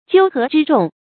纠合之众 jiū hé zhī zhòng
纠合之众发音